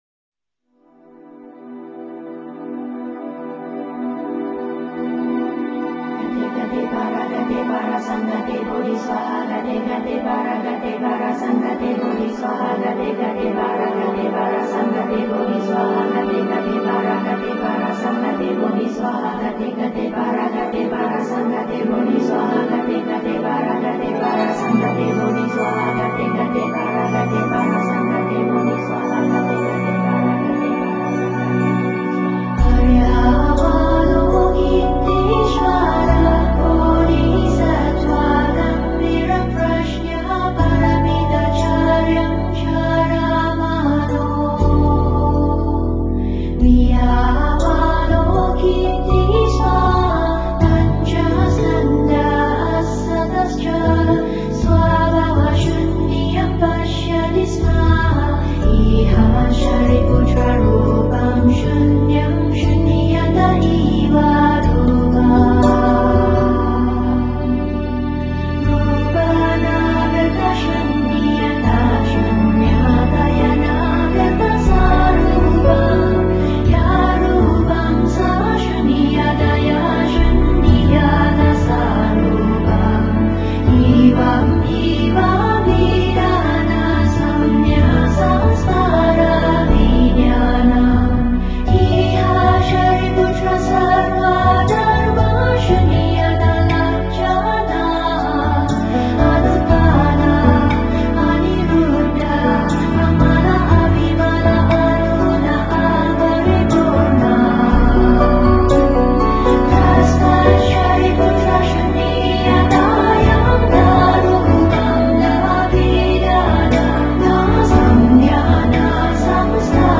聽流轉無限歡喜心的天女之聲 唱誦出心靈最輕盈的喜悅
她的歌聲，平靜、柔和、優美，充滿天女般的空靈之美 她的音樂，清新、自在、抒懷，充滿鬆放心神的新世紀之美